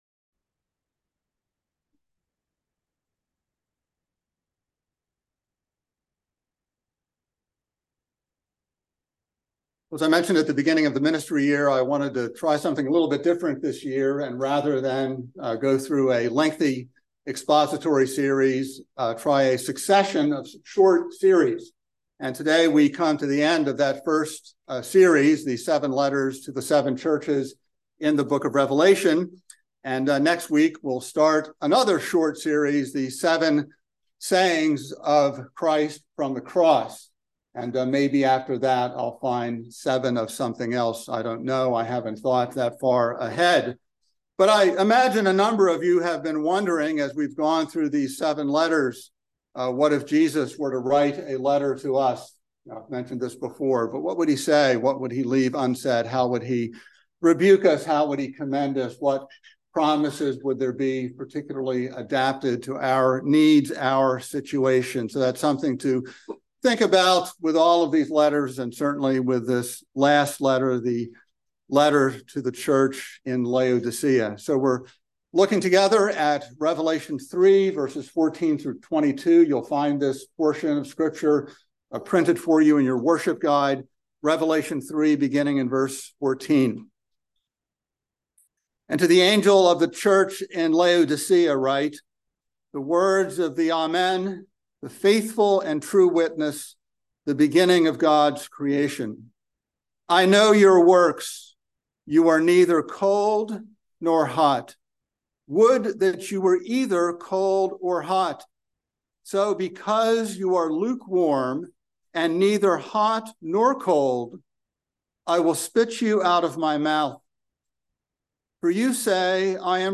by Trinity Presbyterian Church | Nov 13, 2023 | Sermon